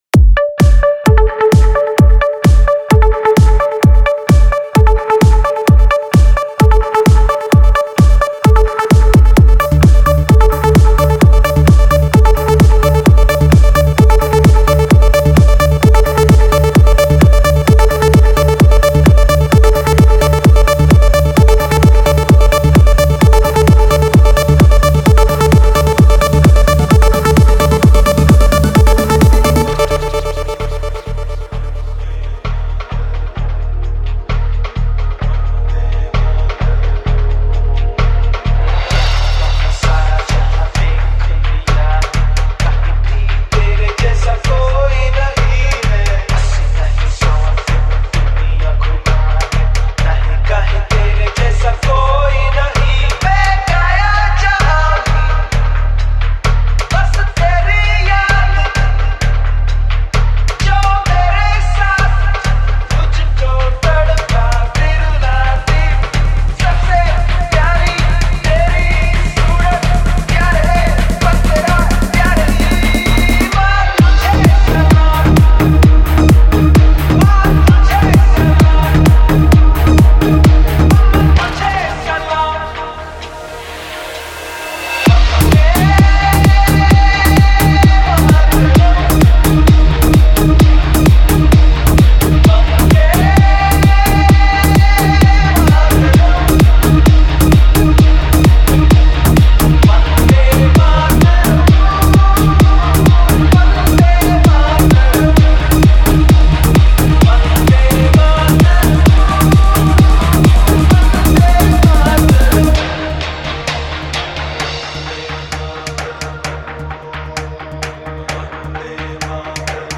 Desh Bhakti Dj Remix Song